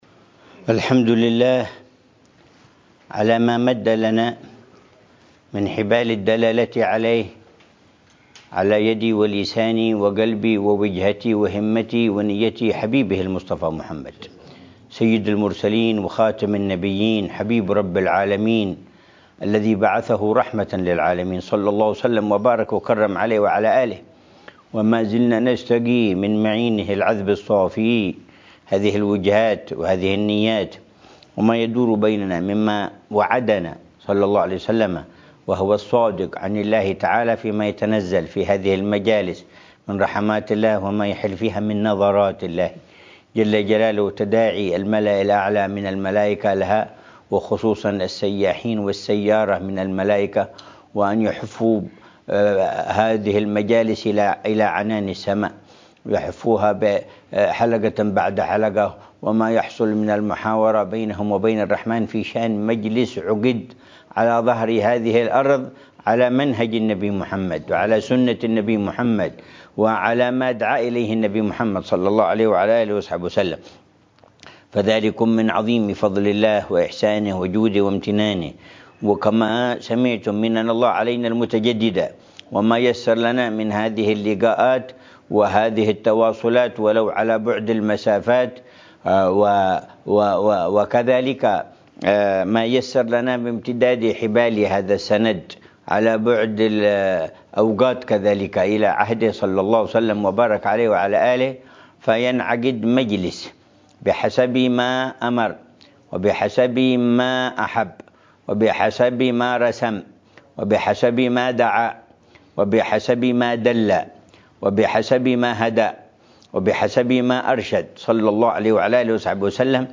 محاضرة العلامة الحبيب عمر بن محمد بن حفيظ عبر الاتصال المرئي في دار المصطفى ضمن سلسلة إرشادات السلوك، ليلة الجمعة 13 جمادى الأولى 1446هـ ، بعنوان: